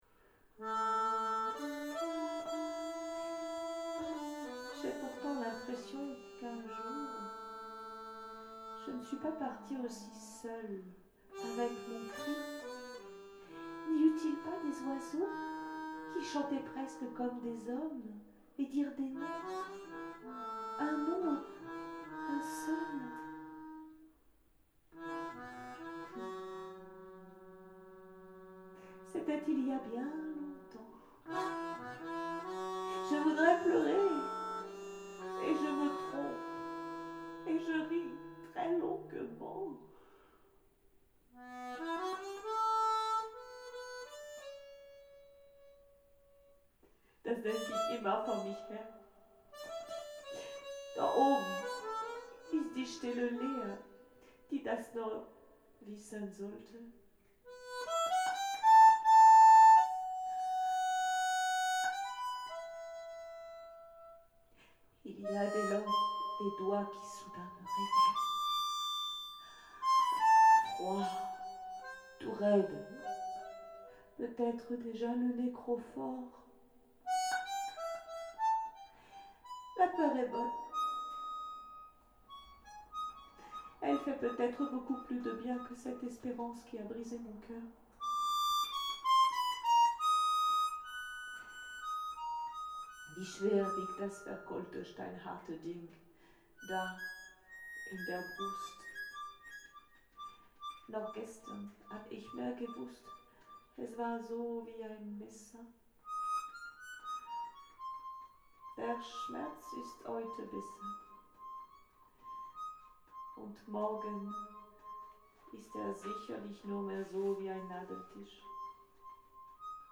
Accordéon / Accordina / Matériau sonore
Abwändigt hängt der Mond in Dunst (répétition) :
La partie sonore est également importante et réussie, minimale dans ses moyens comme dans ses effets, elle a avant tout une fonction rythmique, mais aussi par moment une dimension symbolique, notamment lorsque le burin cogne la pierre jusqu’à la briser.